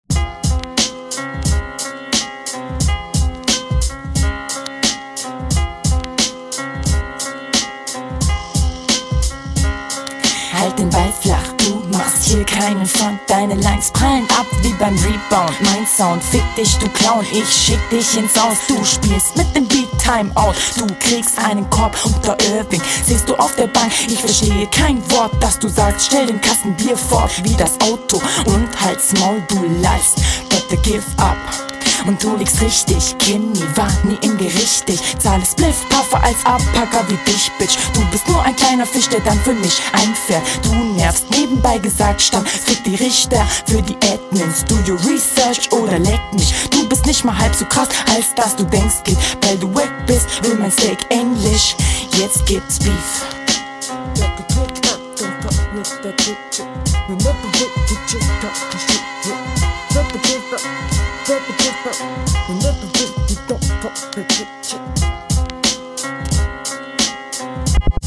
Flow: Flow an sich ist ganz okay, triffst den takt Text: text ist haus maus …